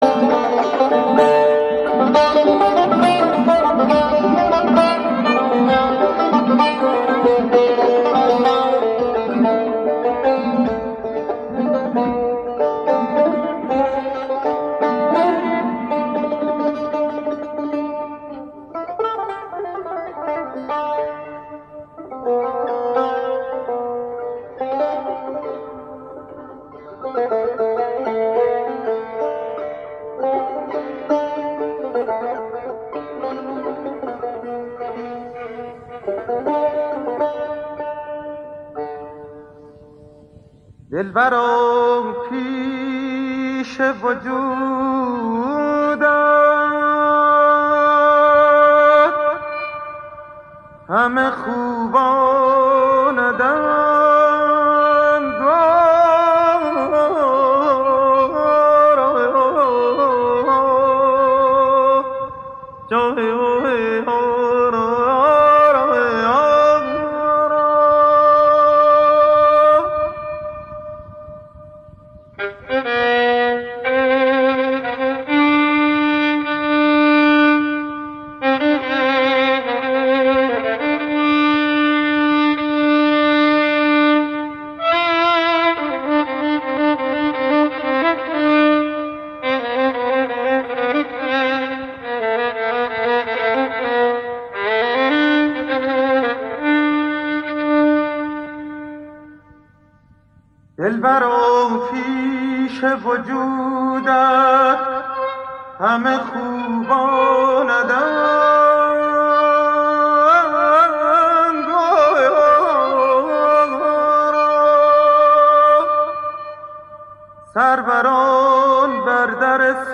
موسیقی اصیل ایران